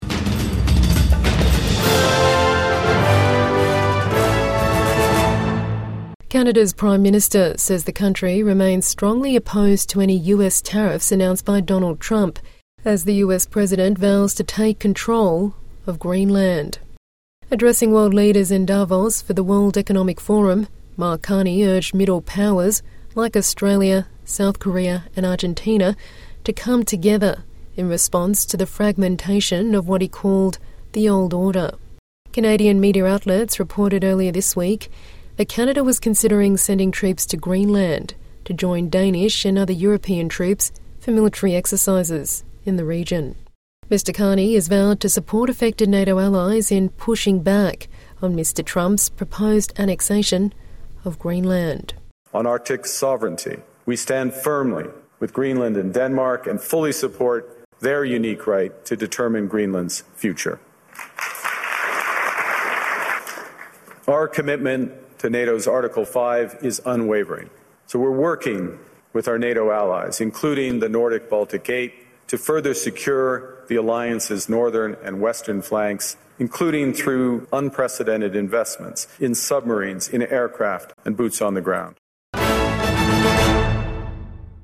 Canada's Prime Minister declares the old order is not coming back in Davos speech